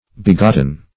Begotten \Be*got"ten\,